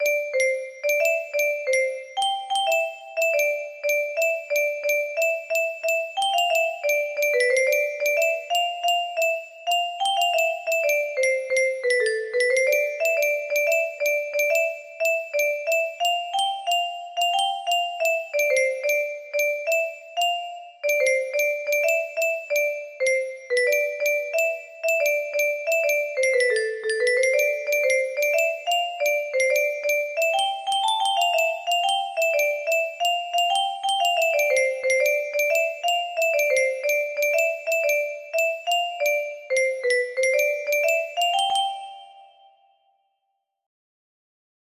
Project 4 music box melody
Yay! It looks like this melody can be played offline on a 30 note paper strip music box!